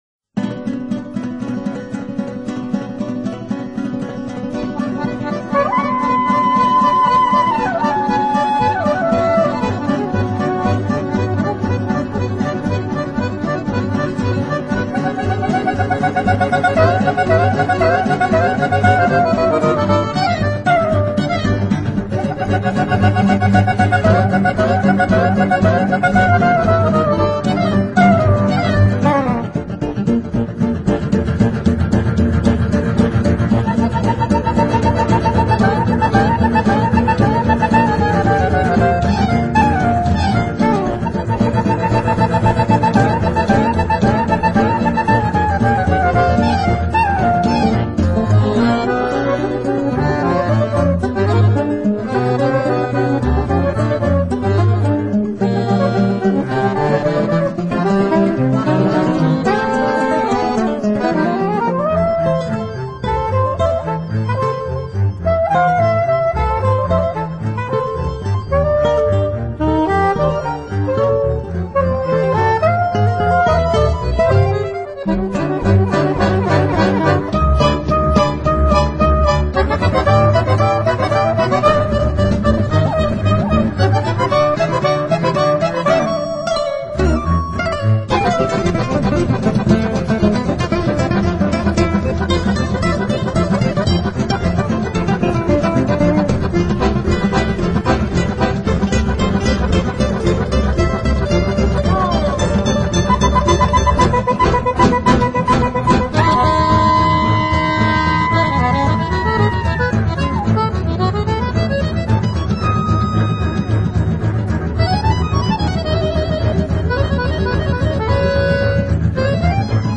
专辑类型：Jazz & Blues, Int’l & World Music
俐落的吉它，配上超重低音的牛筋，浪漫的手风
琴和感性的萨士风，真是浪漫到极致了，舒缓的旋律令人忘却烦忧。